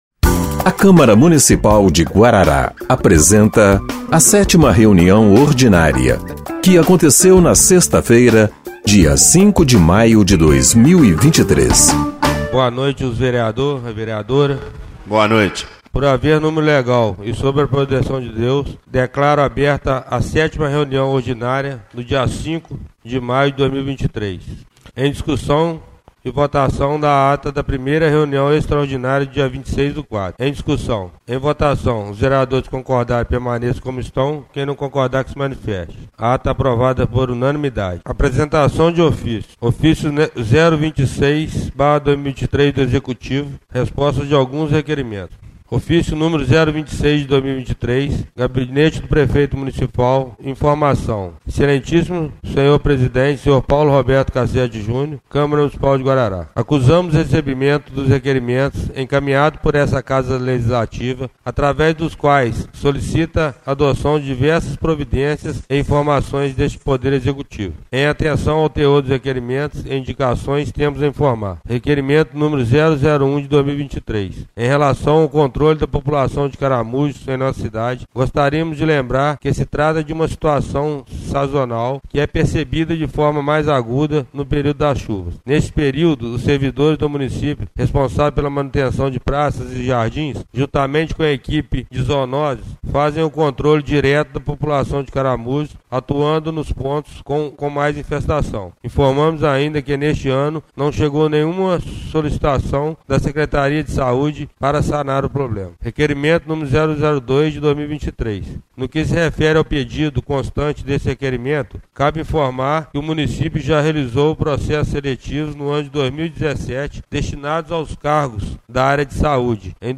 7ª Reunião Ordinária de 05/05/2023